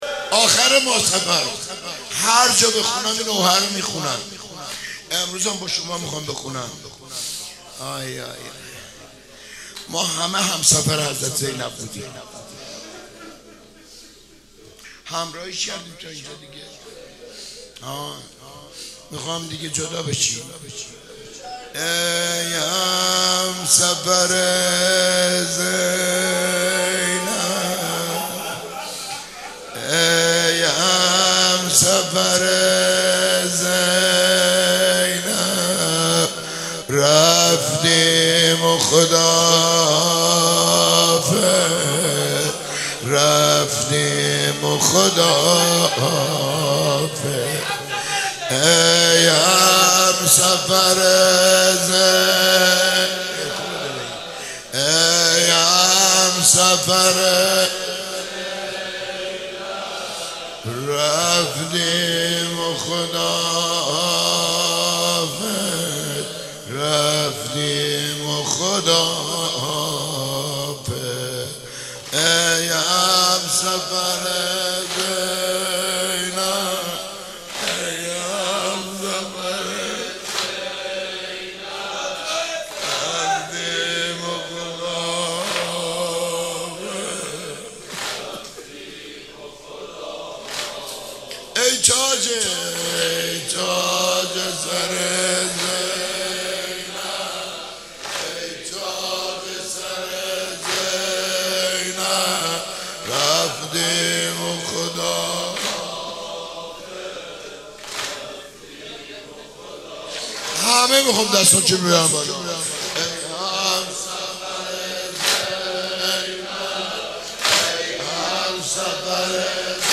شب 28 صفر - روضه